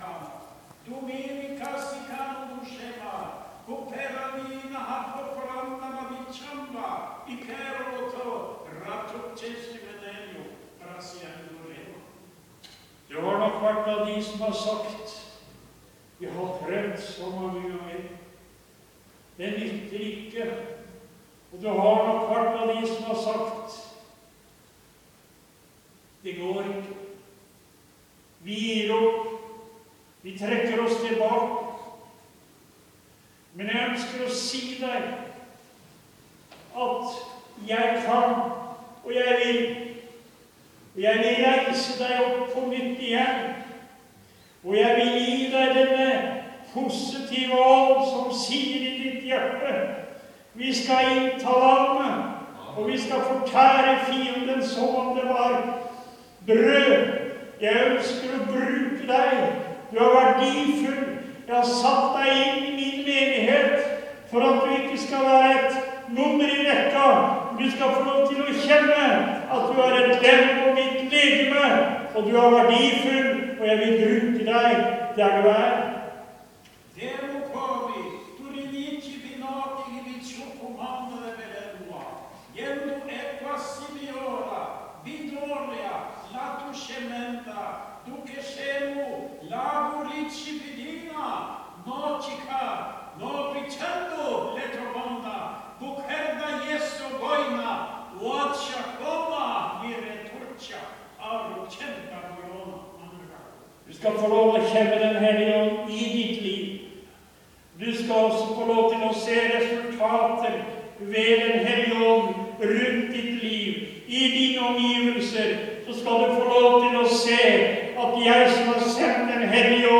Møte i Maranatha 8.9.2013.